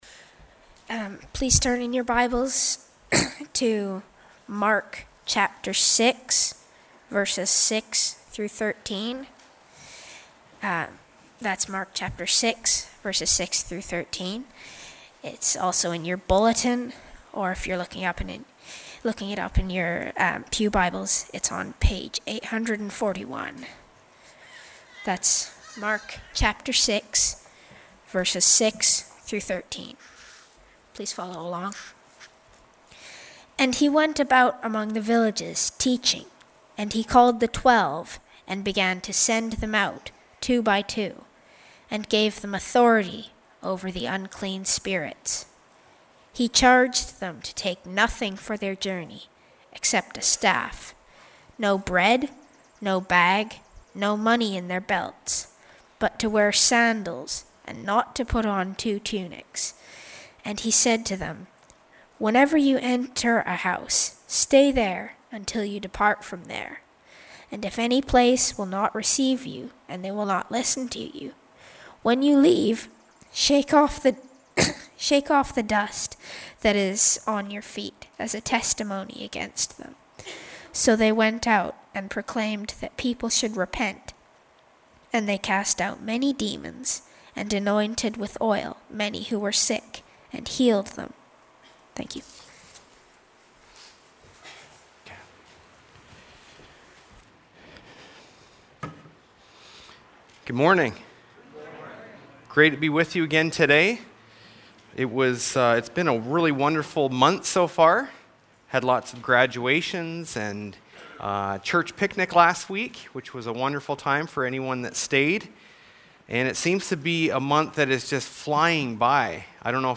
Sermons | Grace Baptist Church